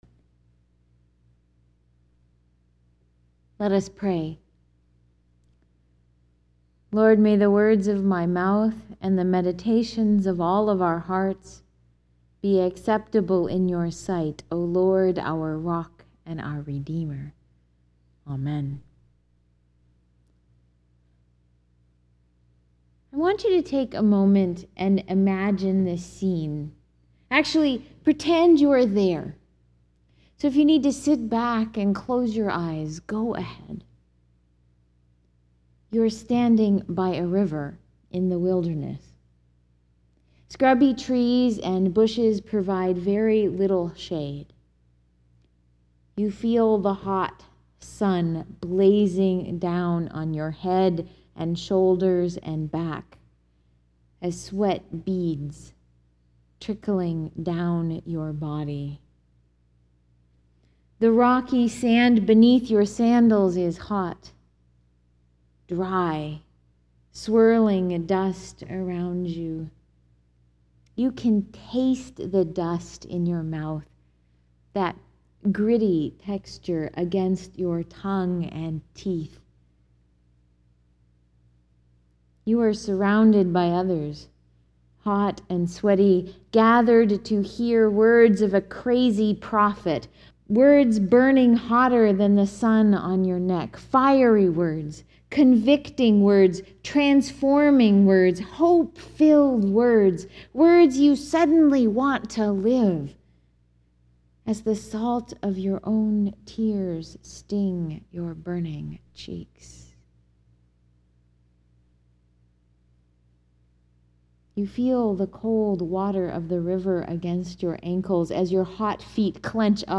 ** I tend not to post the whole text of my Sunday sermons online; instead, I post an audio file for you to listen to—so, if you click on the play button below the text references, you can listen to me preach the sermon (make sure your browser and plugins are up to date—sometimes the little player doesn’t load if you aren’t up to date).